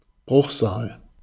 Audioaufzeichnung der Aussprache eines Begriffs. Sprache InfoField Deutsch Transkription InfoField Bruchsal Datum 14.